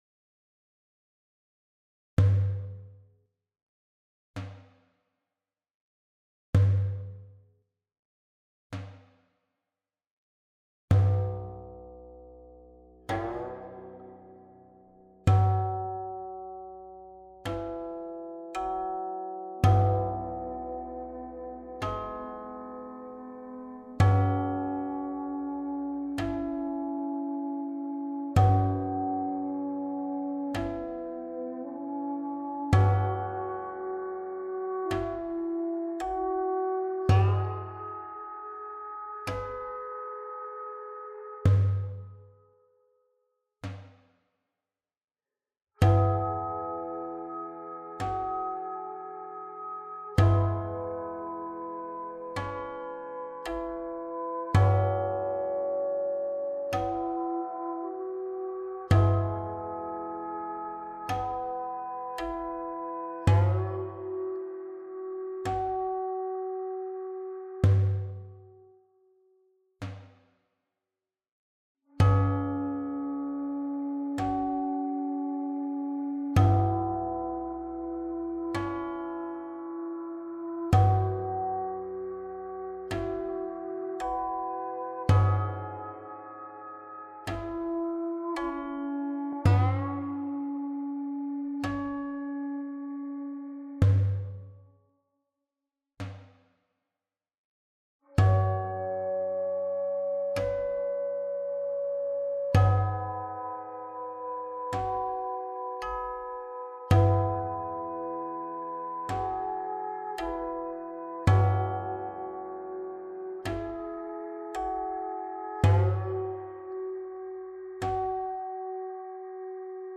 黃鐘徵 · 徵調式（取250.56Hz為黃鐘）
黃鐘起調 · 清黃鐘畢曲
伴奏版：這是從簫聲部與古琴聲部的合奏；
其中，簫採用了二聲部重奏來演繹寬廣的空間；古琴的泛音勉強替代了鐘磬；Daff鼓用來代替傳統的大鼓和小鼓。古琴的散音淡淡的為樂曲鋪墊了厚重感，以試圖彌補古琴泛音和Daff鼓的輕薄音色之不足。
這套樂曲使用東方的五度相生律（三分損益法生律），取250.56Hz為黃鐘，這相當於A4=422.82Hz。